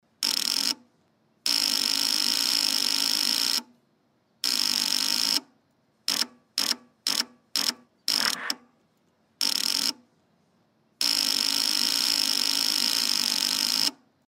Звуки сломанной машины
Звук неисправного стартера автомобиля: